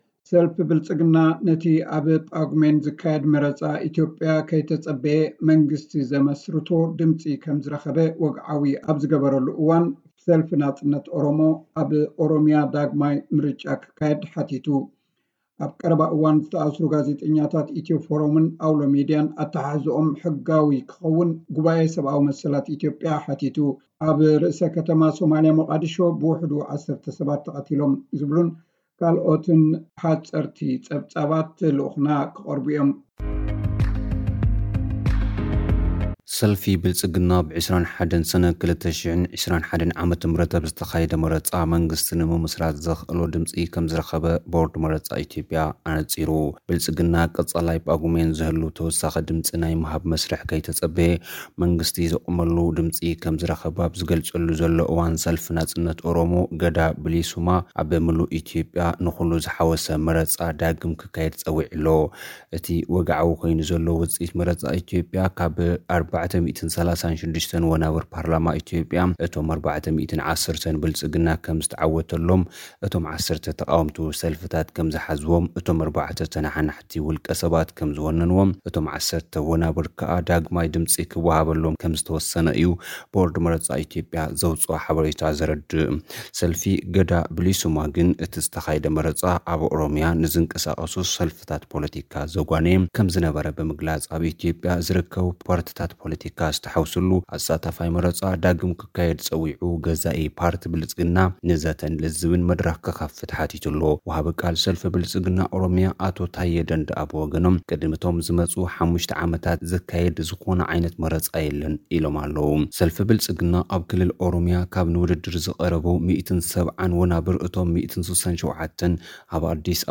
ሰልፊ ብልጽግና መንግስቲ ዘመስርቶ ድምጺ ይረክብ፤ ሰልፊ ናጽነት ኦሮሞ ኣብ ኦሮምያ ዳግማይ መረጻ ክካየድ ይሓትት። (ሓጺር ጸብጻብ)